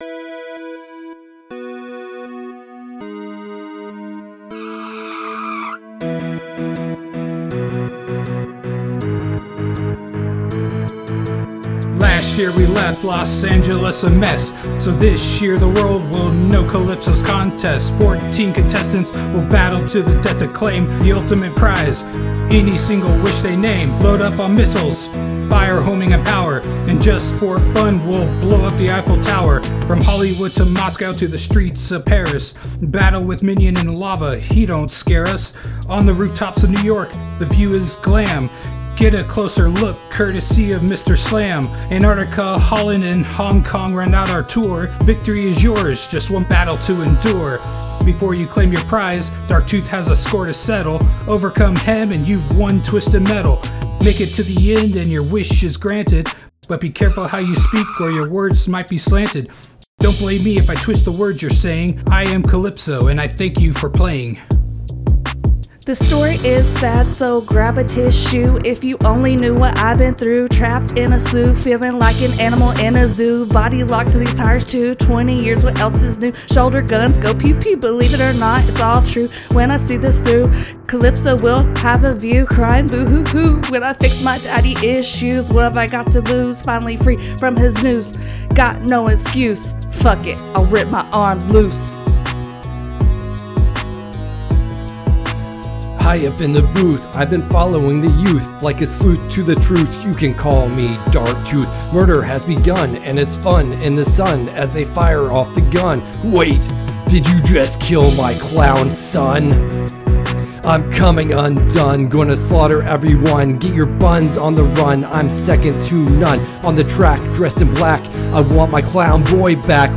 Rap from Episode 61: Twisted Metal 2 – Press any Button
Twisted-Metal-2-rap.mp3